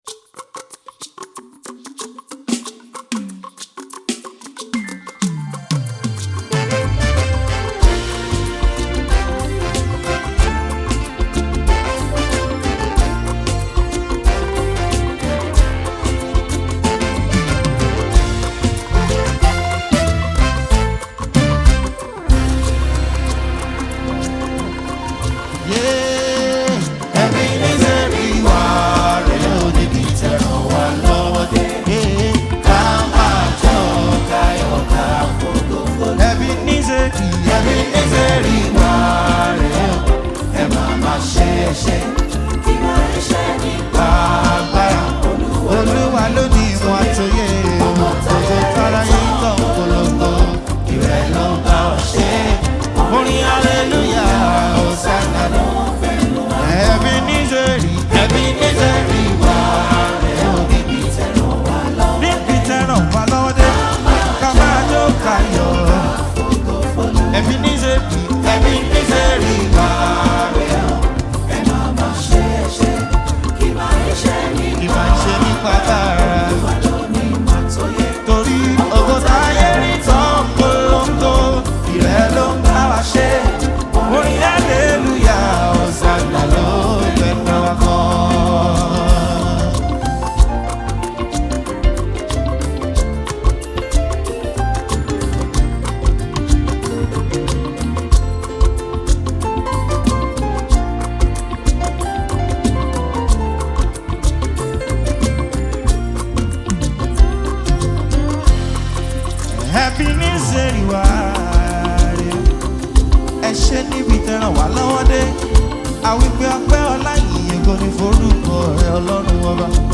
The renowned Christian music team of praisers and worshipers
praise worship